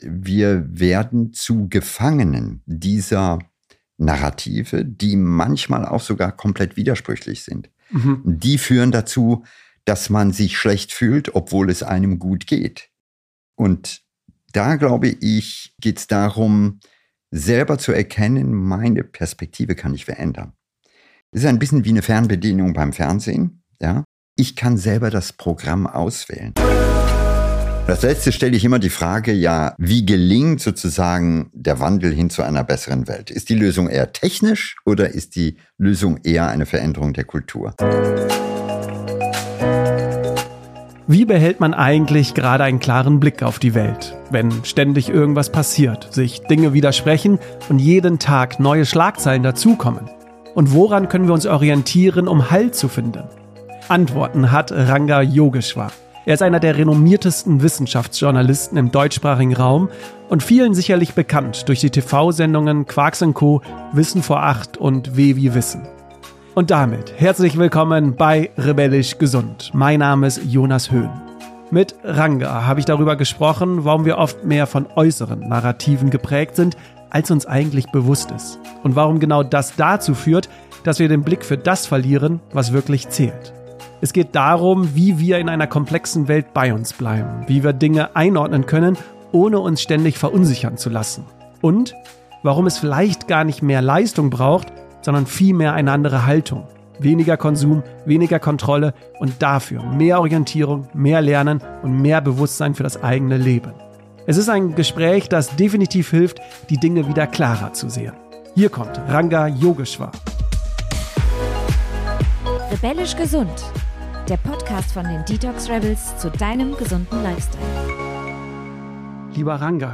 Ein Gespräch, das hilft, die Dinge wieder klarer zu sehen.